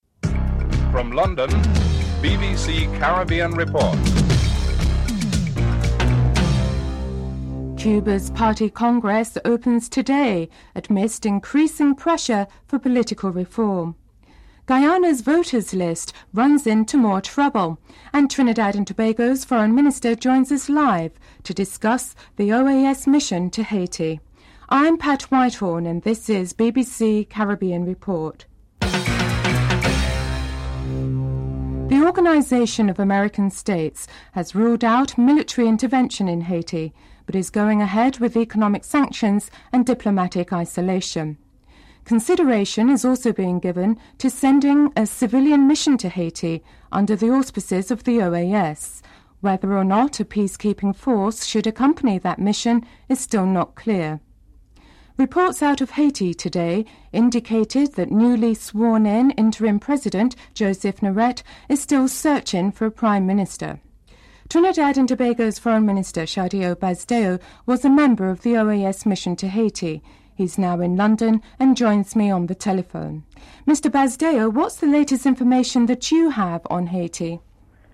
Interview with Trinidad and Tobago Foreign Minister, Sahadeo Basdeo, a member of the OAS mission to Haiti (00:32-05:27)